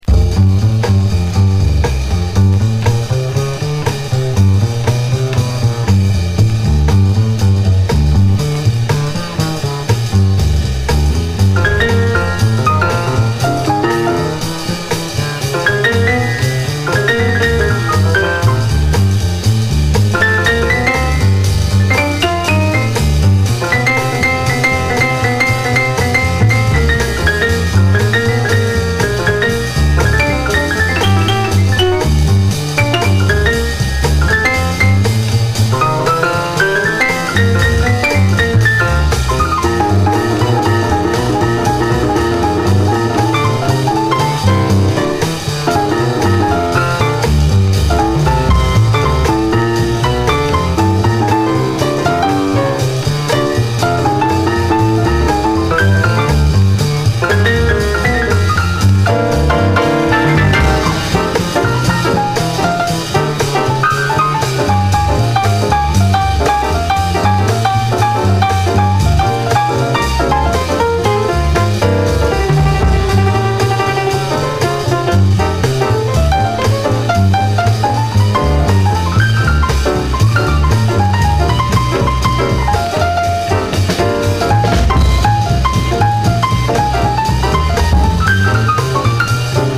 SOUL, 60's SOUL, JAZZ, OLDIES, 7INCH
荒ぶるイントロのウッド・ベースがカッコいい、4ビートのジャズ・ダンサー！